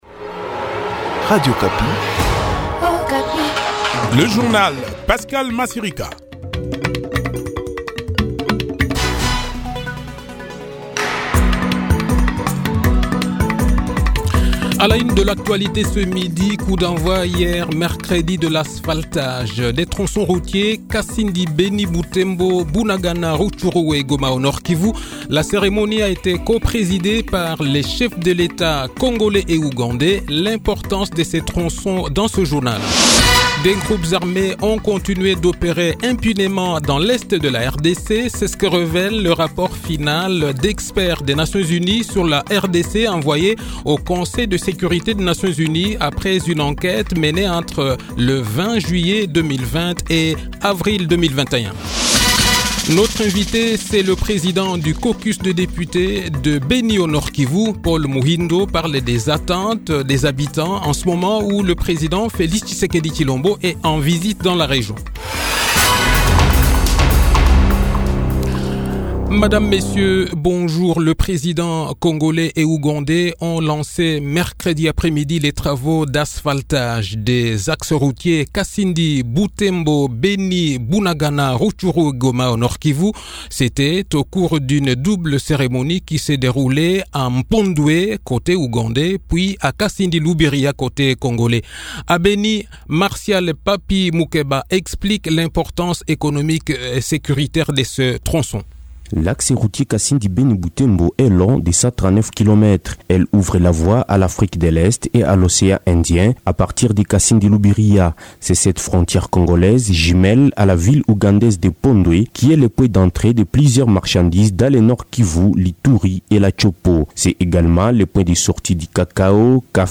Le journal-Français-Midi